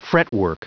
Prononciation du mot fretwork en anglais (fichier audio)
Prononciation du mot : fretwork